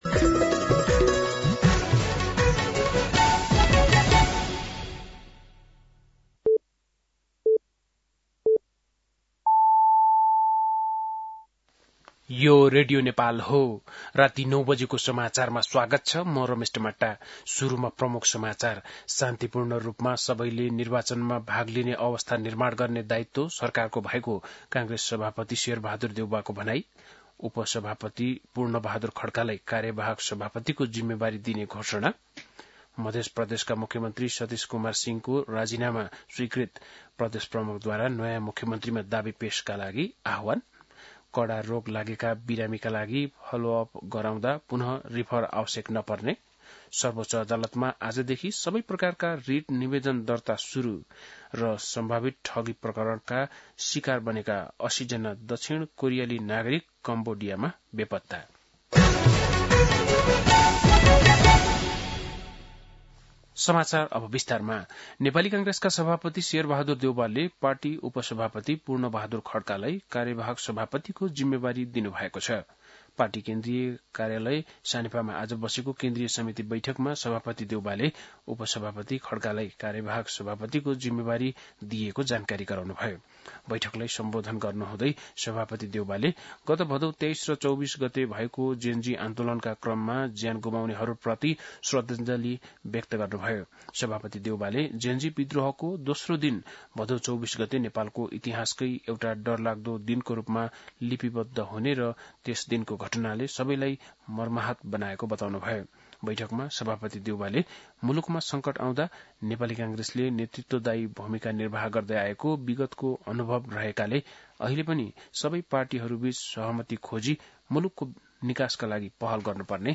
बेलुकी ९ बजेको नेपाली समाचार : २८ असोज , २०८२